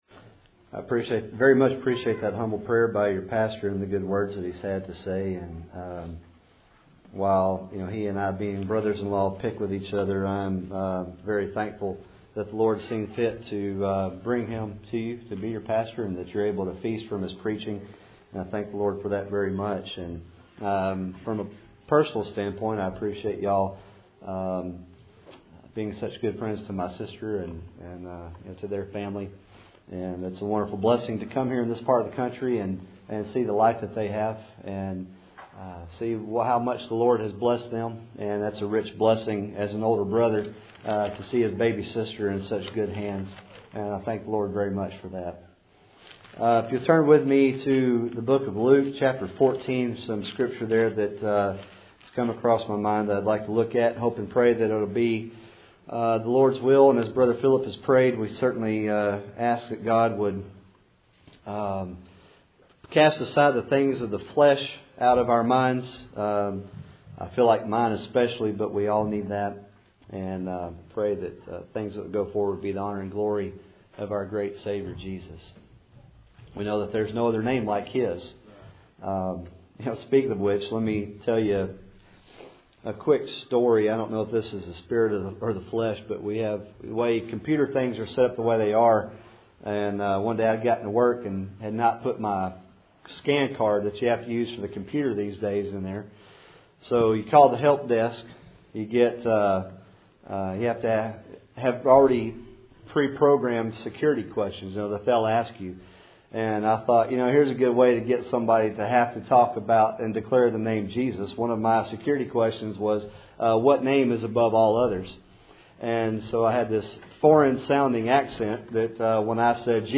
Passage: Luke 14:25-27 Service Type: Cool Springs PBC Sunday Evening